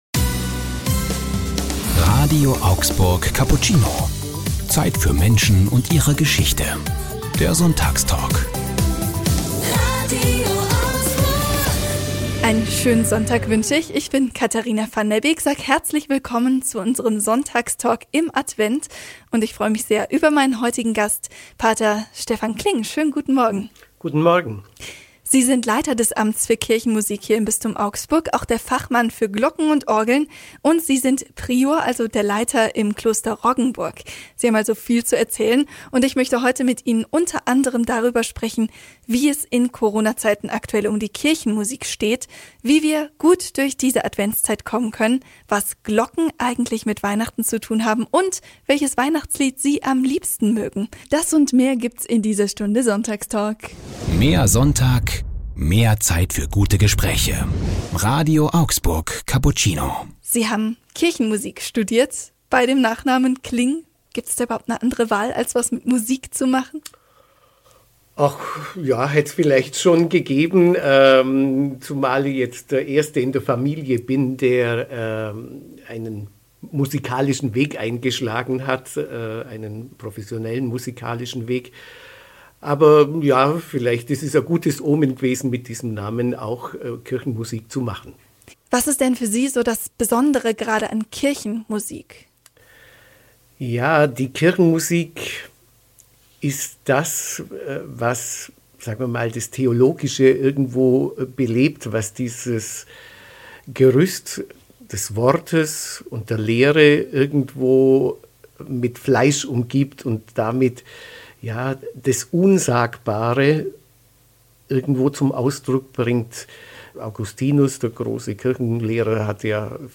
Sonntagstalk im Advent